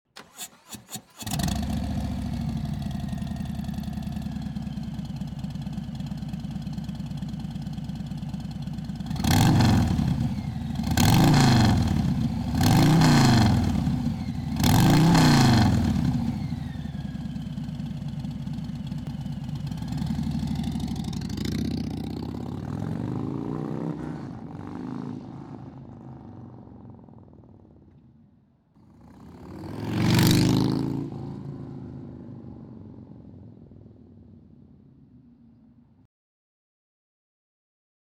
Crafted for the bold, this system redefines the game with a perfect blend of aggressive style, raw power and a bold exhaust note that will keep you in the throttle mile after mile.